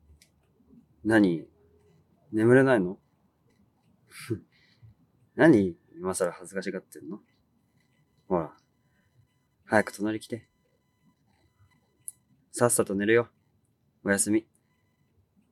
タクヤ おやすみボイス